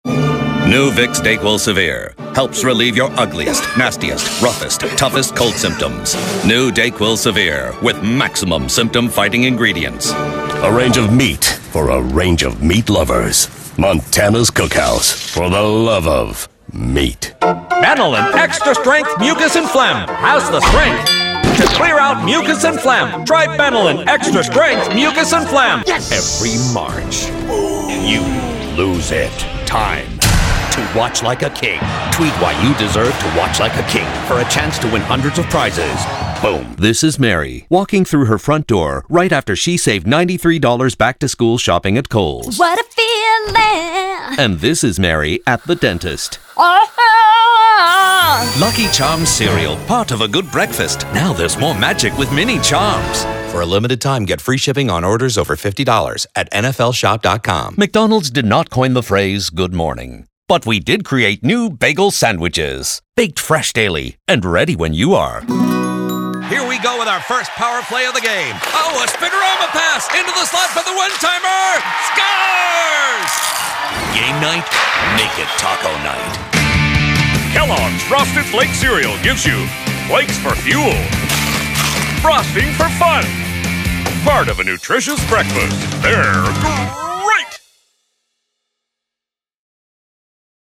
Announcer Demo